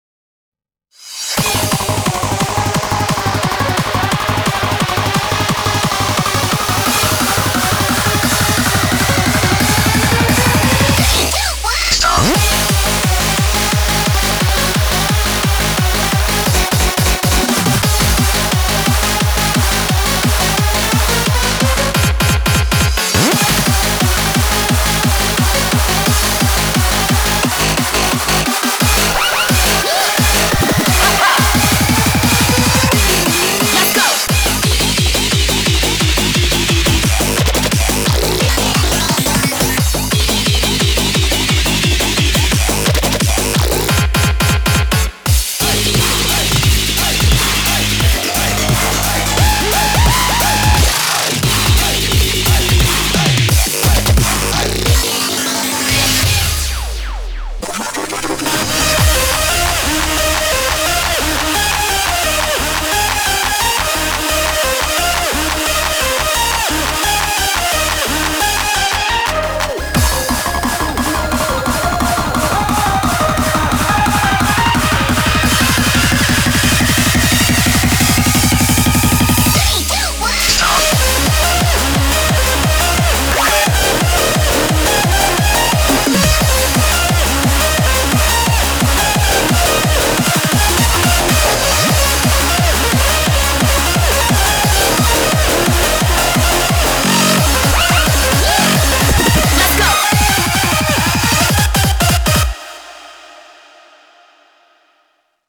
BPM88-175
Audio QualityPerfect (High Quality)